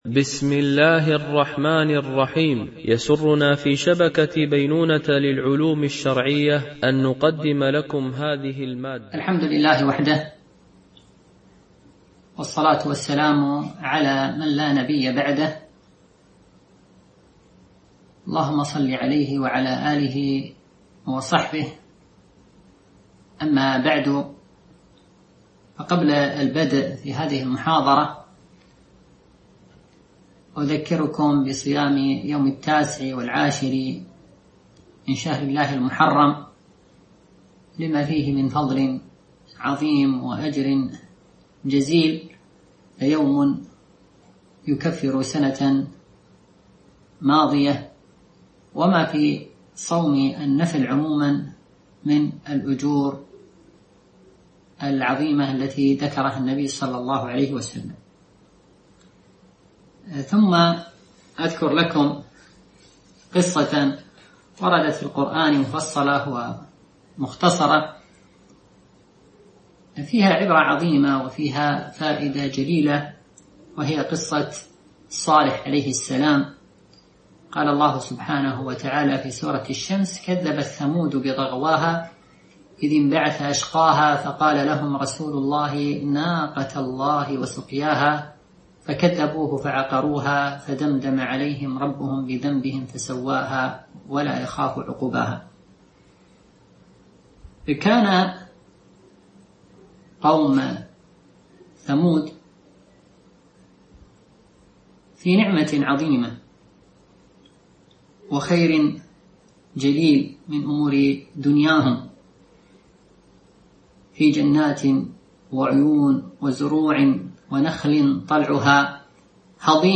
سلسلة محاضرات نسائم إيمانية وقيم أخلاقية